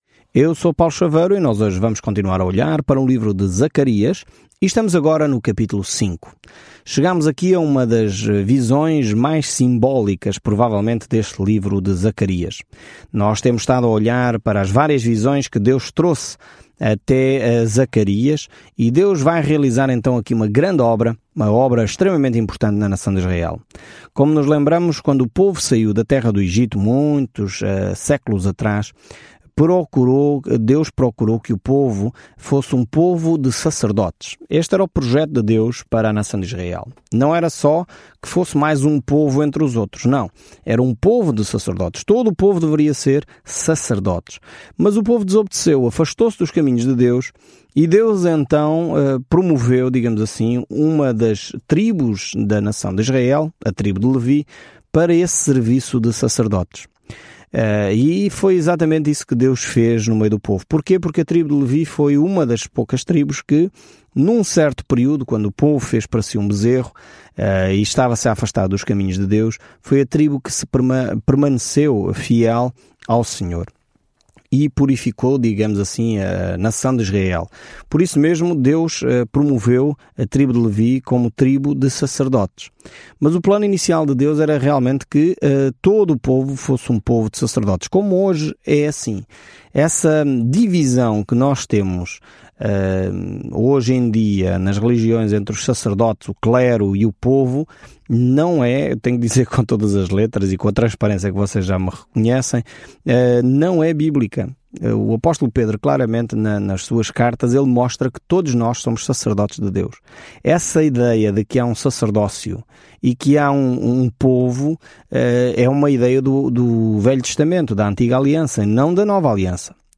Scripture Zechariah 5:1-4 Day 10 Start this Plan Day 12 About this Plan O profeta Zacarias partilha visões das promessas de Deus para dar às pessoas uma esperança no futuro e exorta-as a regressar a Deus. Viaje diariamente por Zacarias enquanto ouve o estudo em áudio e lê versículos selecionados da palavra de Deus.